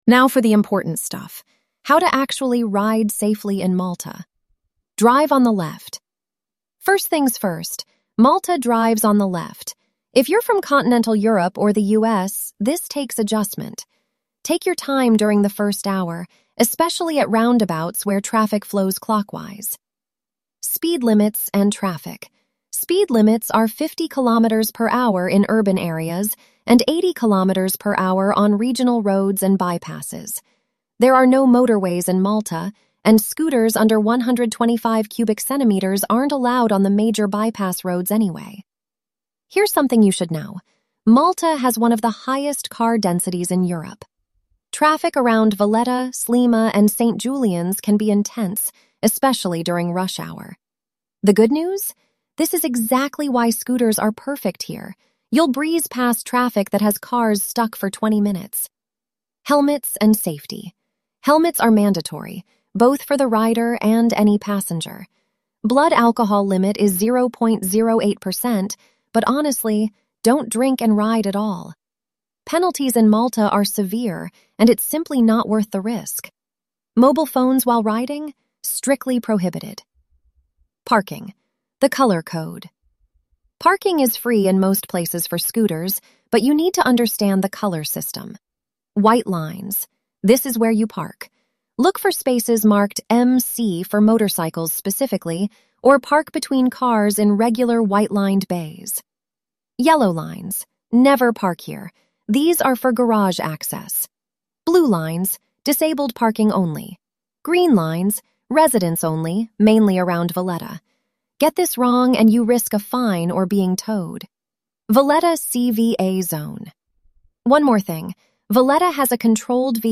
🎧 Malta Scooter Riding Audio Guide